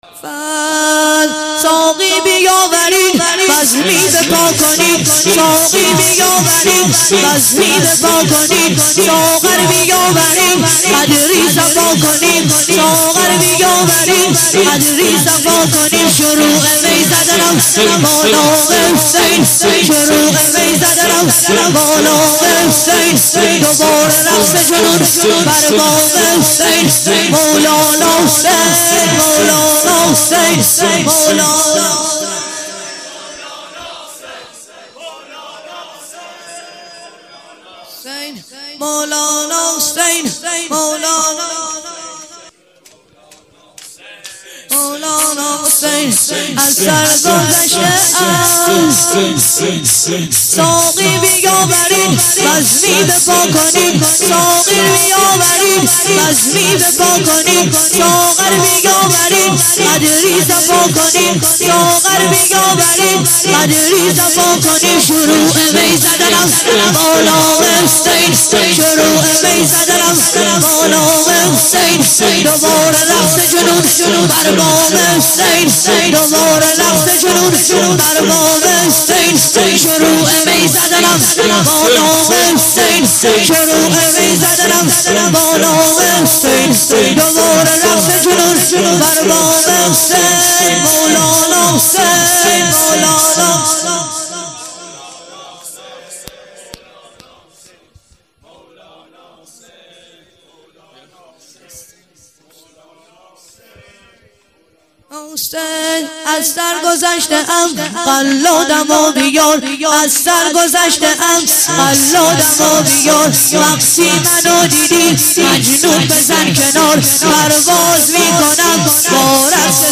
شور - ساقی بیاورید بزمی به پا کنید